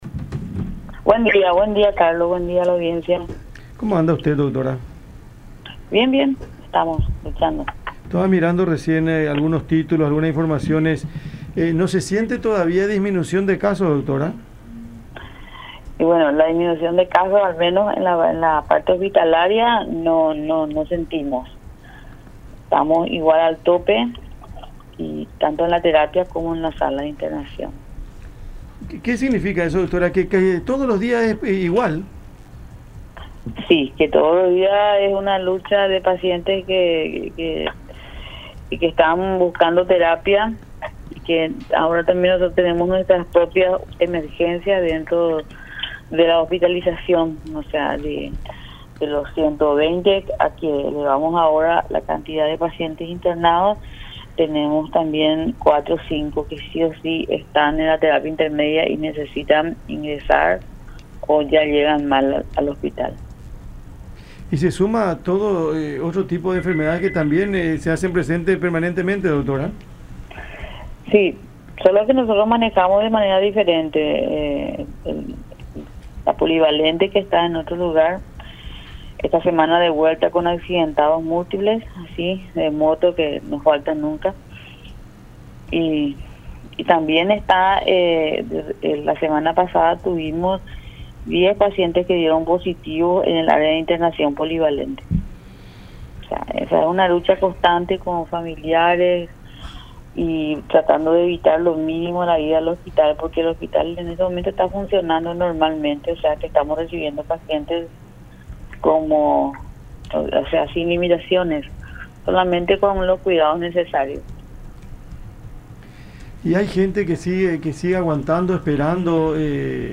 en diálogo con el programa Cada Mañana por La Unión